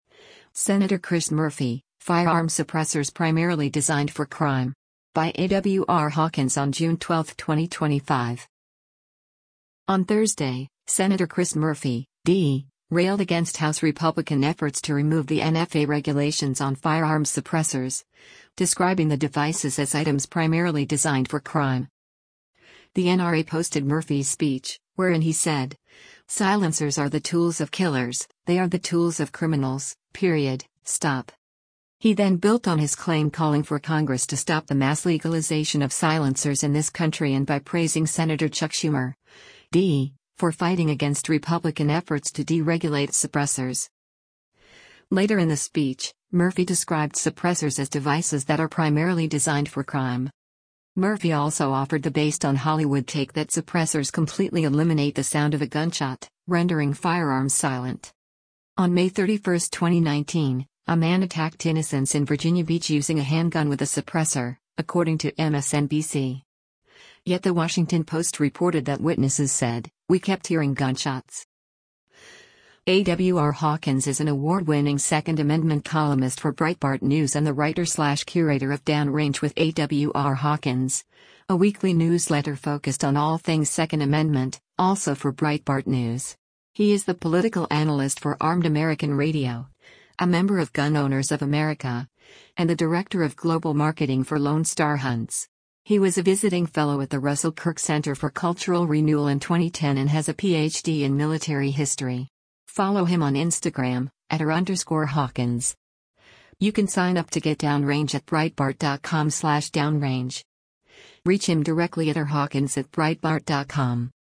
The NRA posted Murphy’s speech, wherein he said, “Silencers are the tools of killers, they are the tools of criminals, period, stop.”